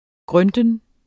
Udtale [ ˈgʁœndən ]